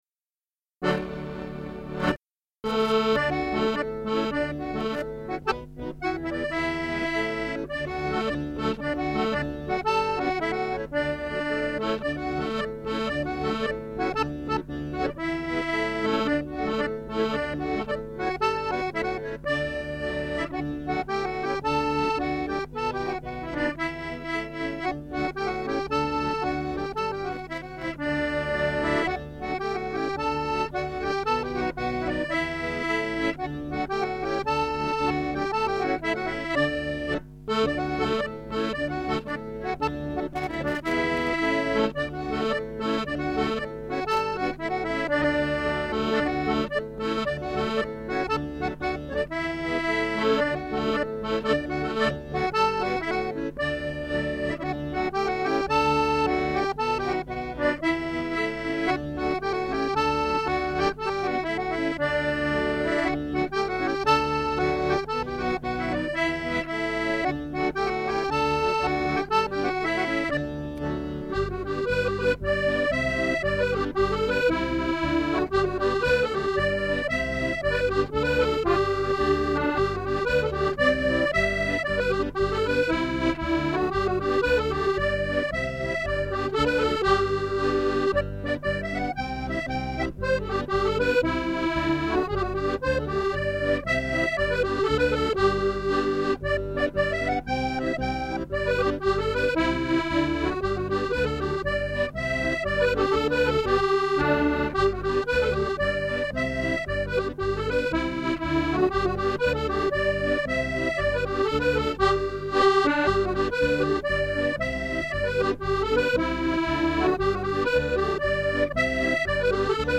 Music - 32 bar jigs or reels
audio/4 x 32 jigs.mp3